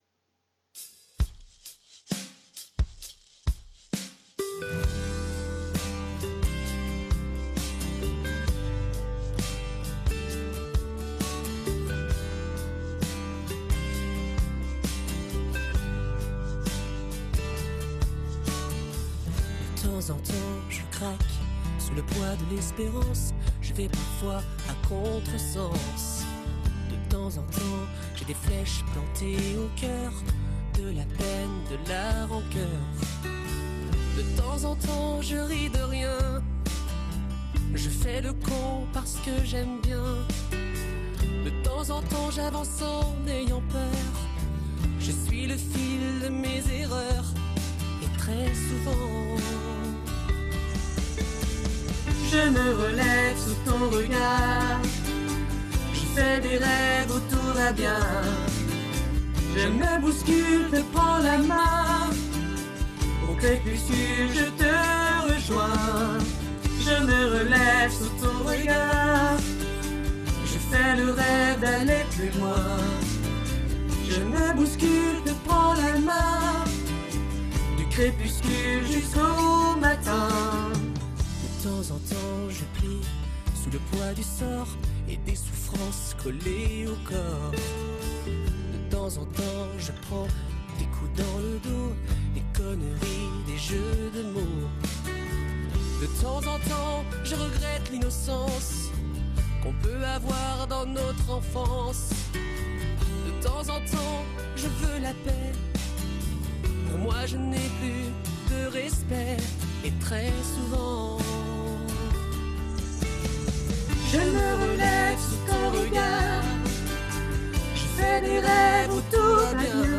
alto.mp3